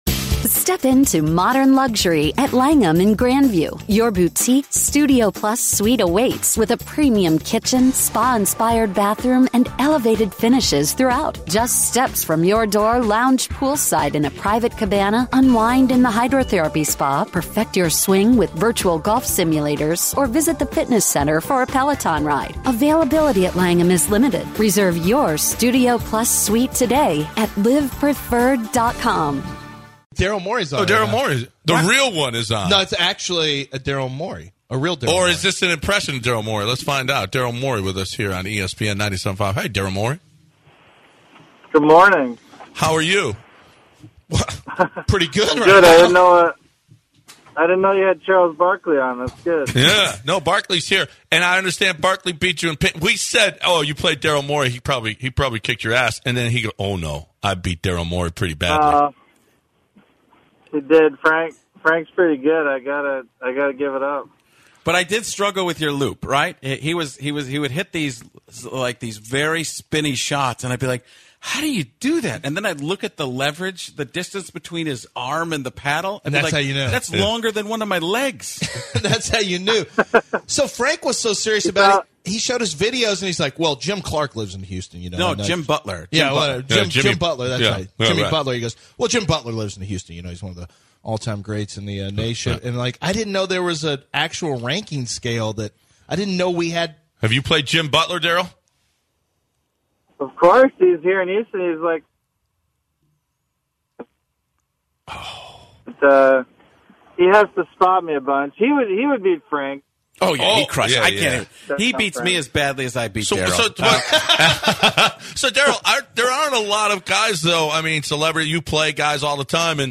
Frank Caliendo was in-studio and Daryl Morey joined the guys to talk about the playoffs.